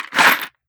Ammo Pickup 002.wav